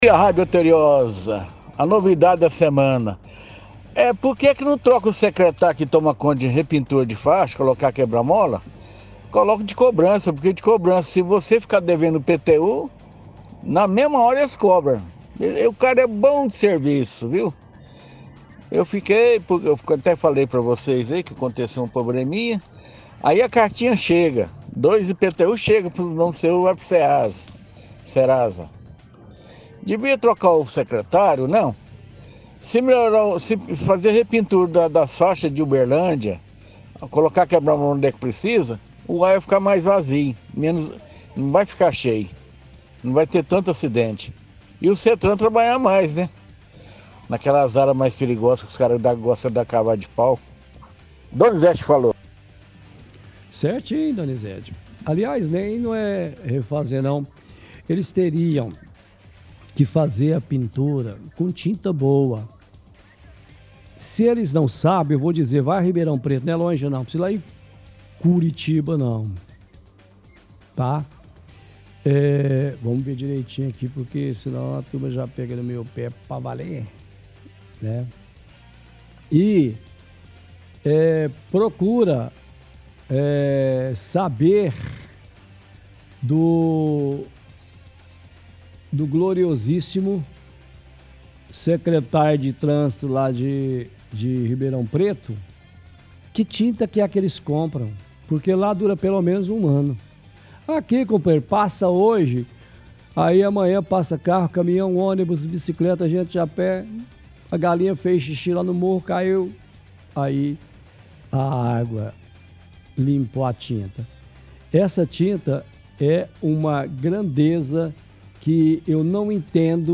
– Ouvinte cobra secretário responsável por re-pintar quebra-molas e faixas nas ruas de Uberlândia.